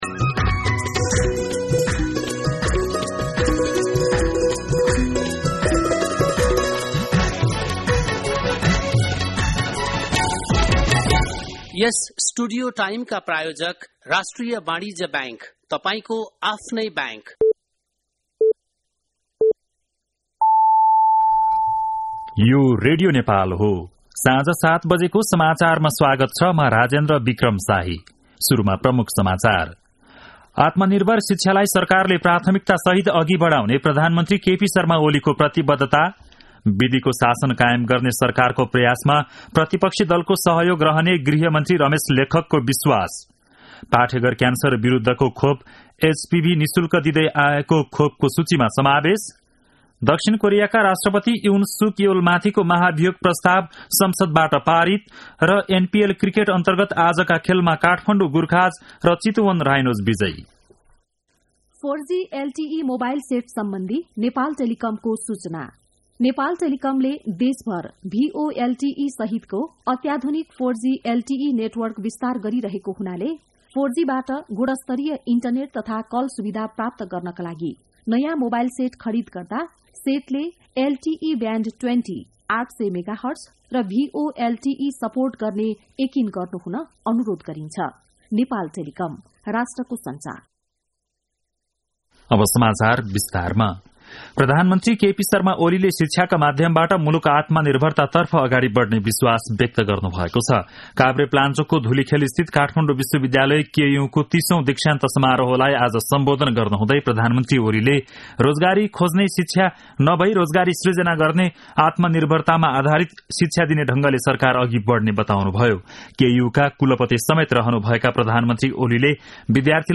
बेलुकी ७ बजेको नेपाली समाचार : ३० मंसिर , २०८१